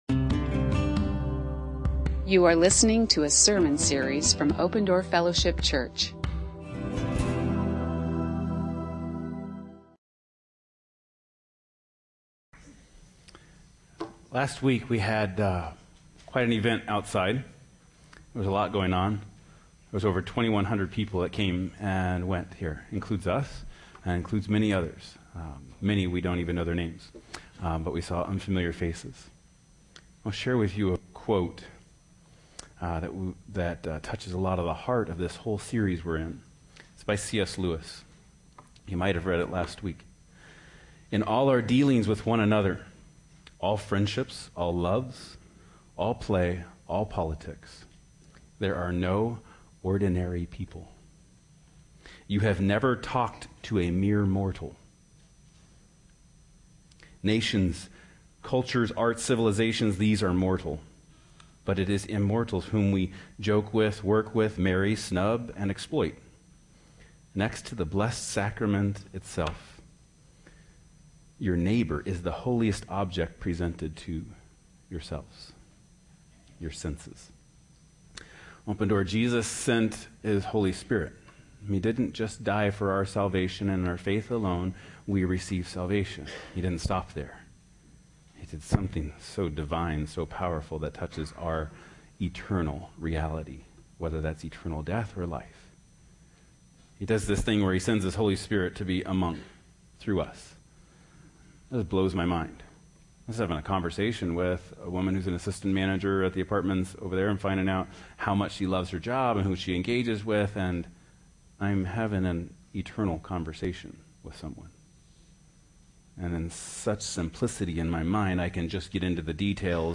You are listening to an audio recording of Open Door Fellowship Church in Phoenix, Arizona.